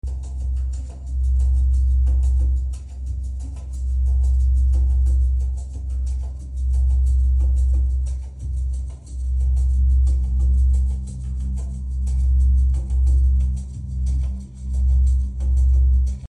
Sound bath tonight at our sound effects free download
Sound bath tonight at our sound effects free download By joshuatreesoundbath 0 Downloads 3 weeks ago 16 seconds joshuatreesoundbath Sound Effects About Sound bath tonight at our Mp3 Sound Effect Sound bath tonight at our studio near Joshua Tree.